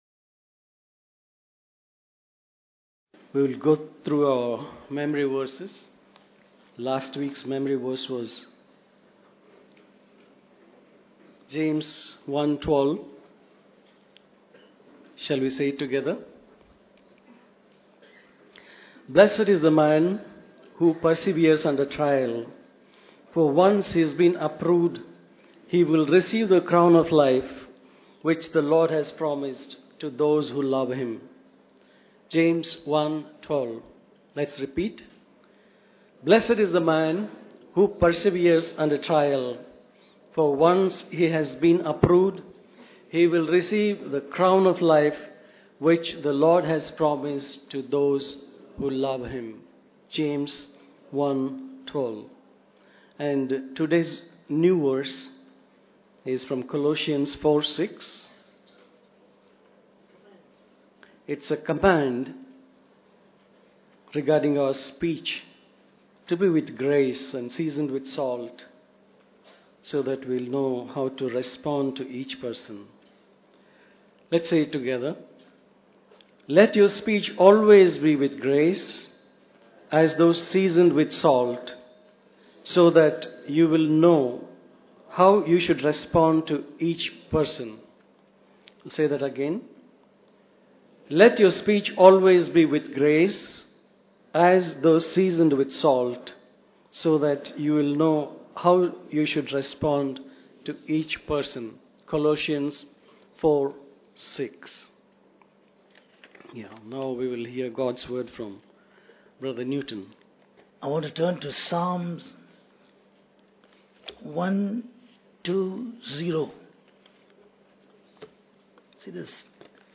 This is the live webcast of the Sunday Church Service from Christian Fellowship Church, Bangalore